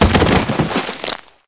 liondead.wav